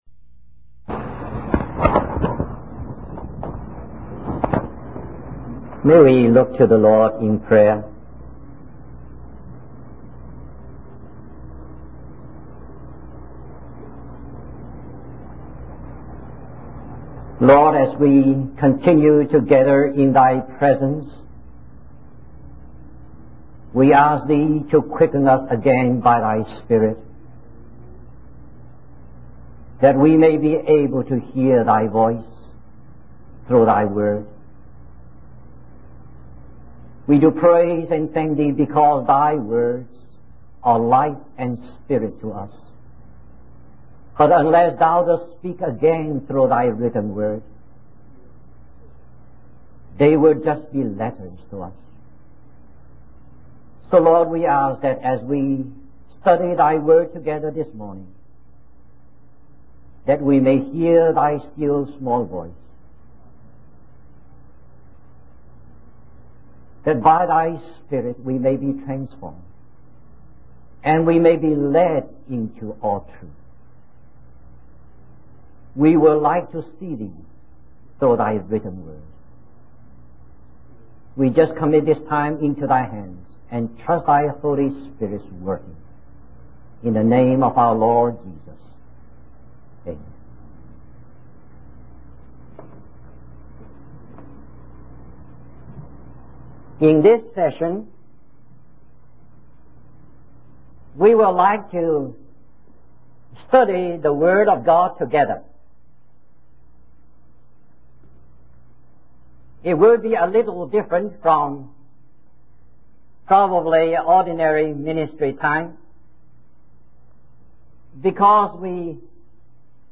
In this sermon, the speaker emphasizes that we are currently living in the last days, which are characterized by difficult times and a lack of love for God.